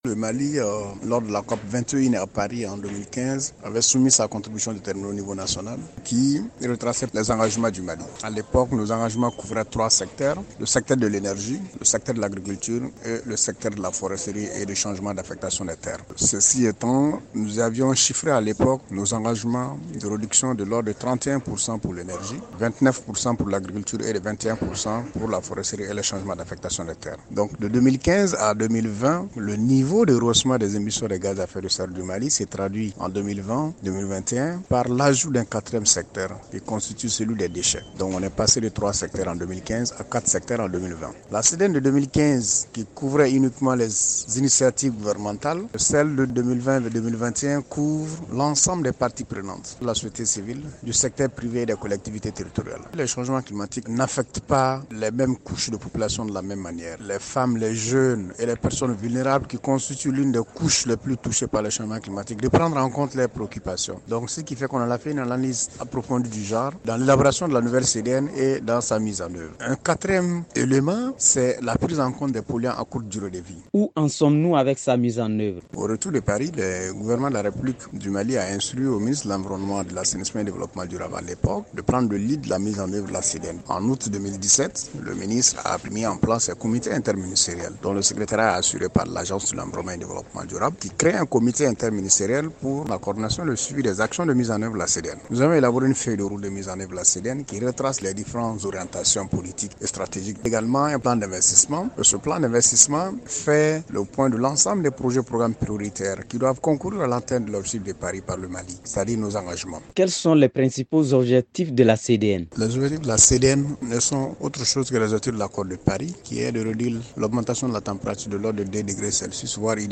Magazine en français: Télécharger